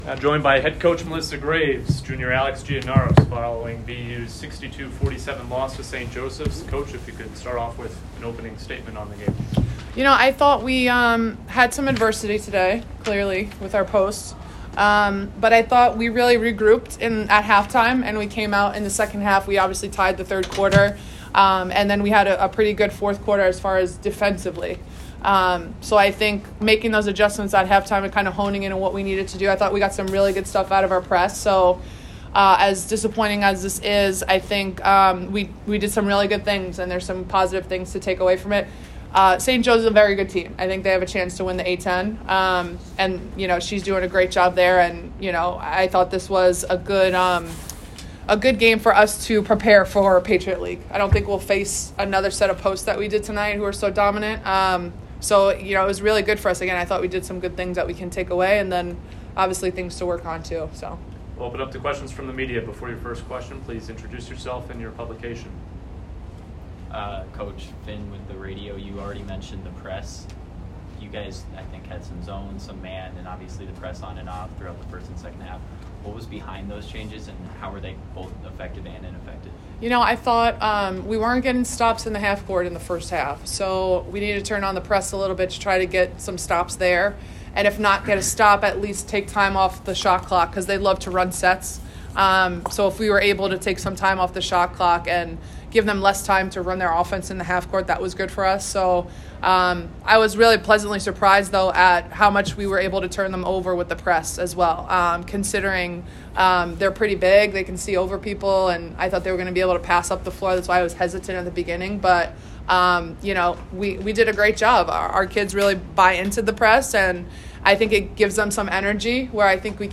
WBB_St_Joes_Postgame.mp3